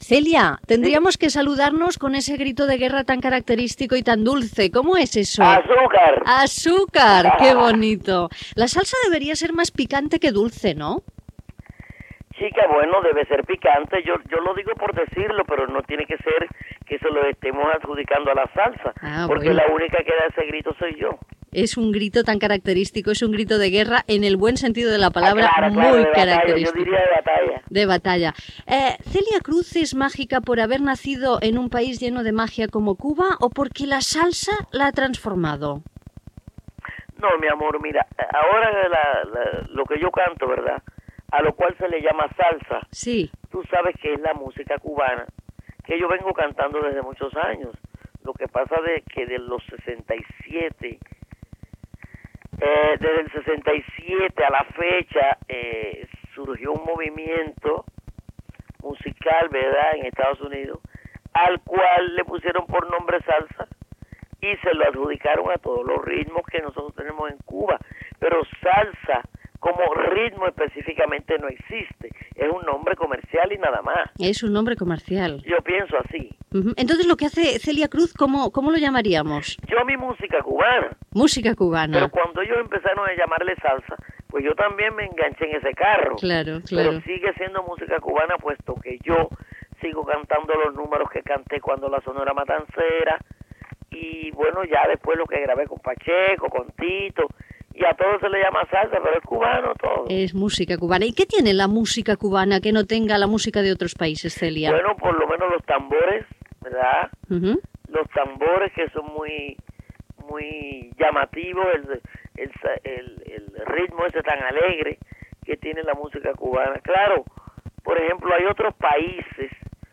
Entrevista a la cantant cubana Celia Cruz que aquell dia va actuar a Barcelona.